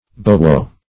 Search Result for " bowwow" : The Collaborative International Dictionary of English v.0.48: Bowwow \Bow"wow`\, n. An onomatopoetic name for a dog or its bark.